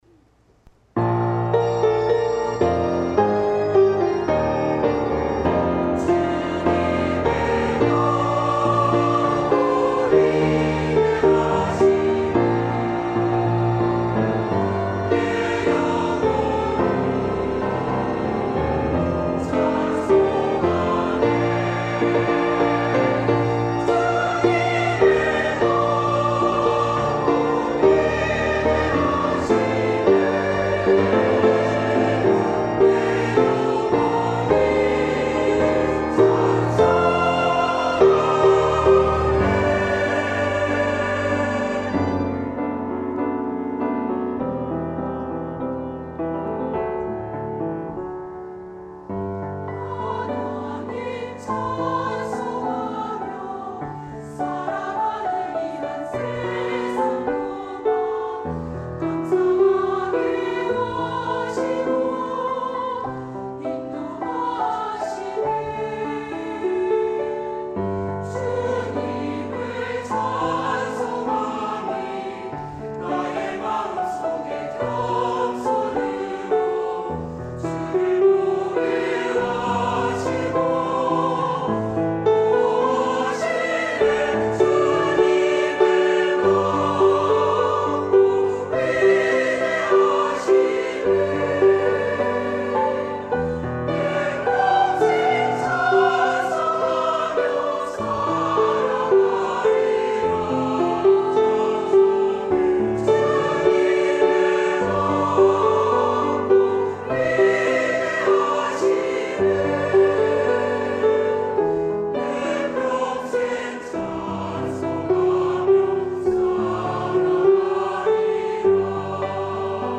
찬양 :: 찬송
찬송 -시온찬양대-